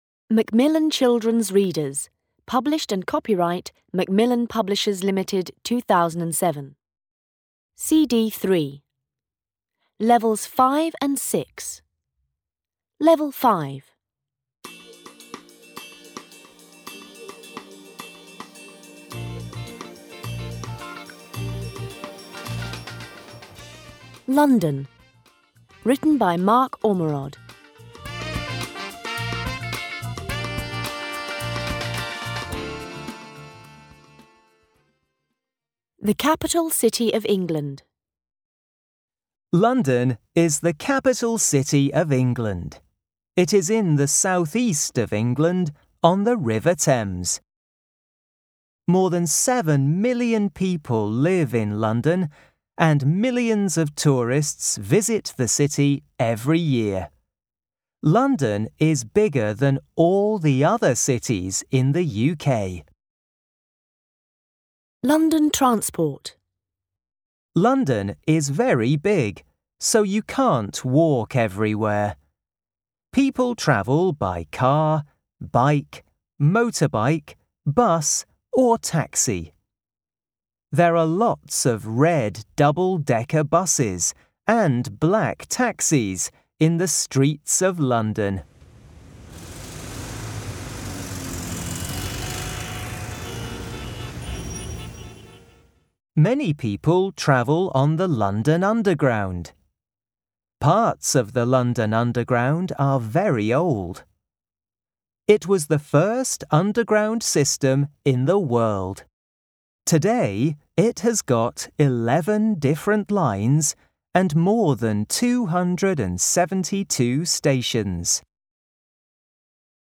• English Type:British English